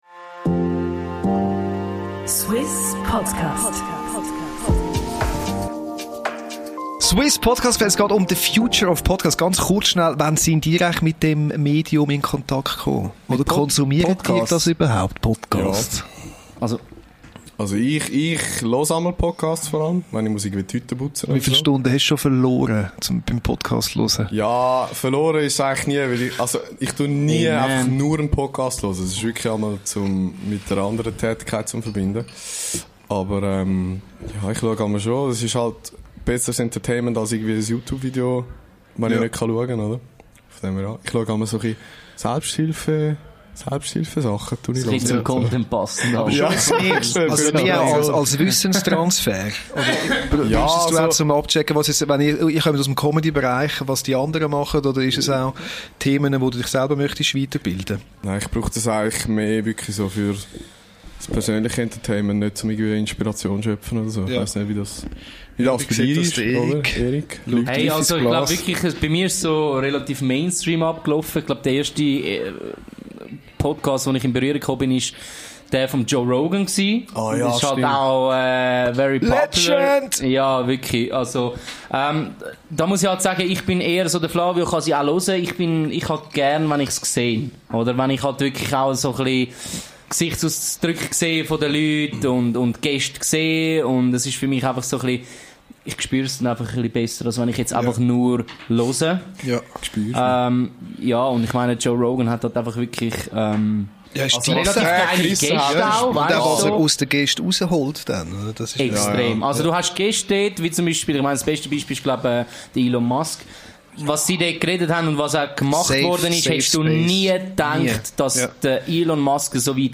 Im Anschluss hat das Trio das Container-Studio von Suisse Podcast besucht
Gespräch